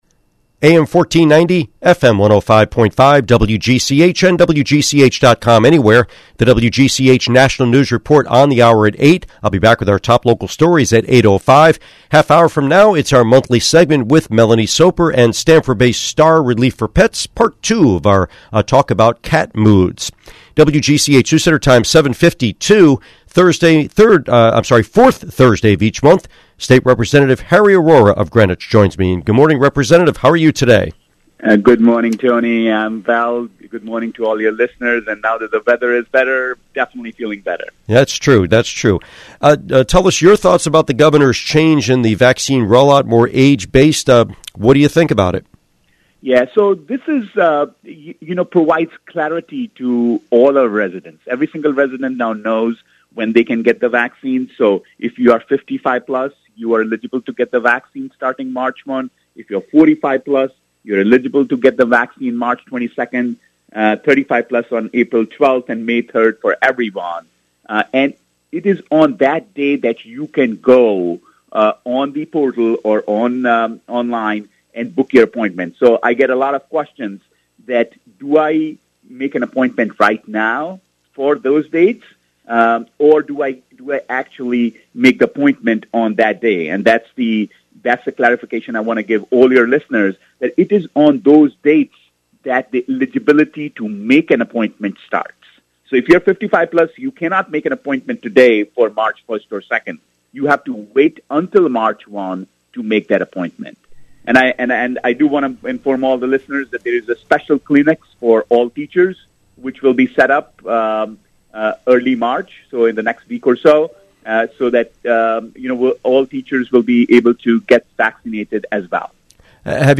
Interview with State Representative Harry Arrora